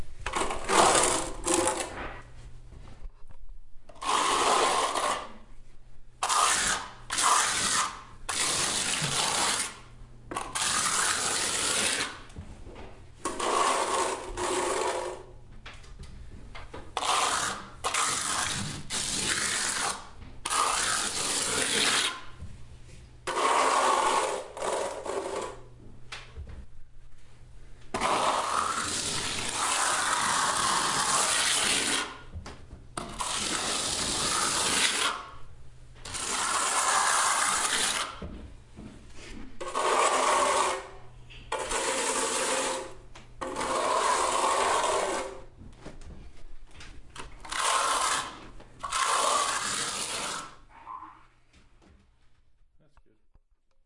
На этой странице собраны звуки штукатурки: от мягкого шуршания до резких скребущих движений.
Шум мастера штукатура в старом здании